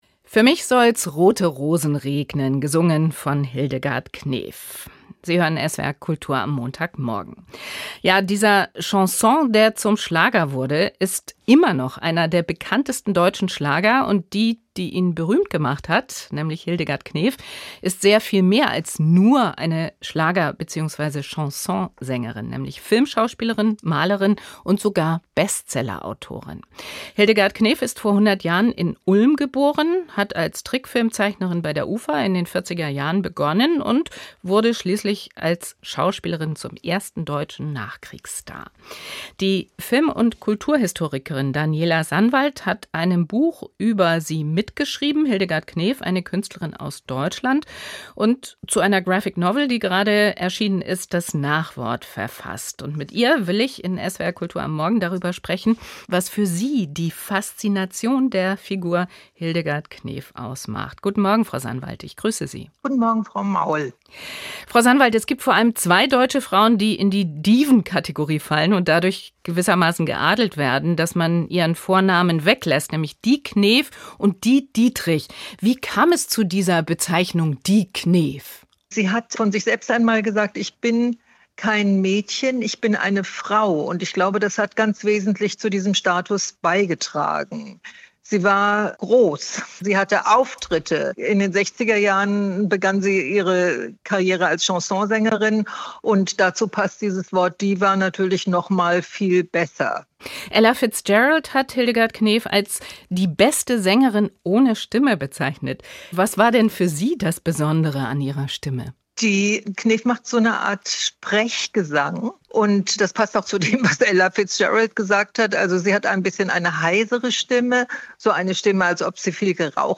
Interview mit